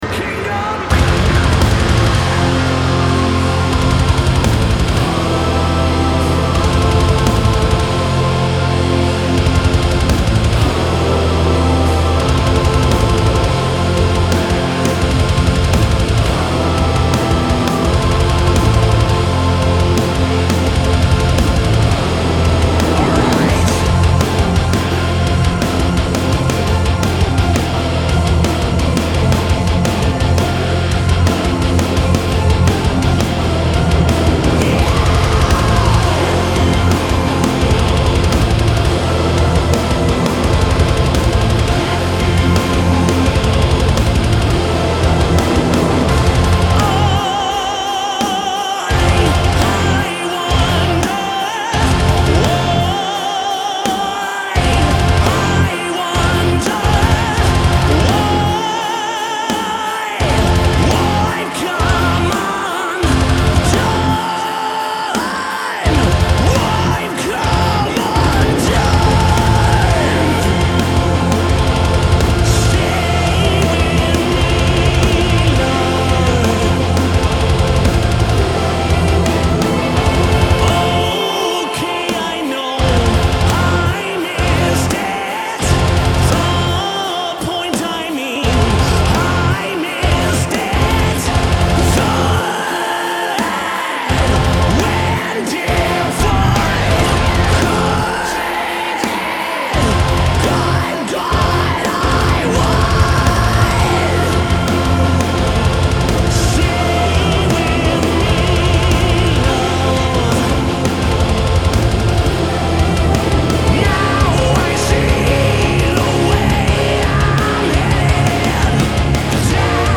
Genre : Progressive Rock, Progressive Metal